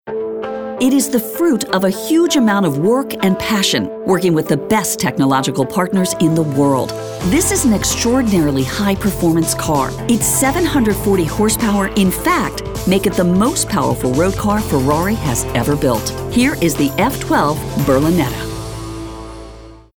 classy, confident, Gravitas, inspirational, mellow, promo, smooth, soccer mom, thoughtful, warm